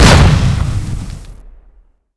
flashbang_explode1.wav